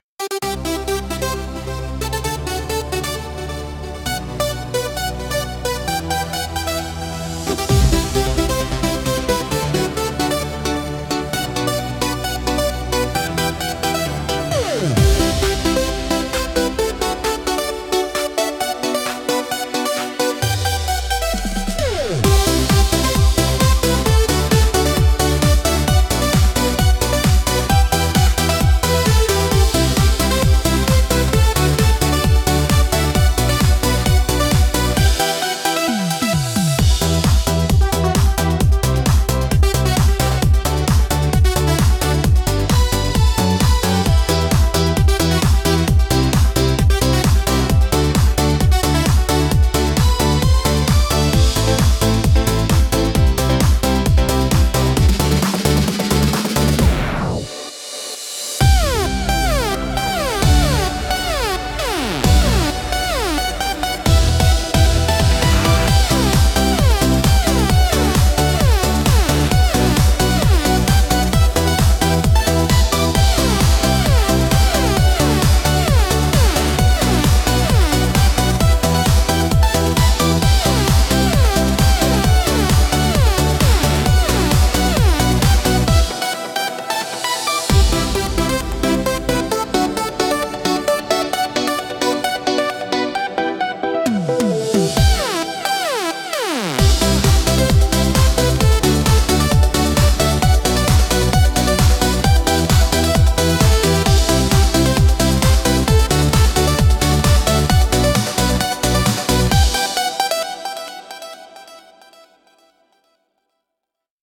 Instrumental - Disco Ball Galaxy 1.54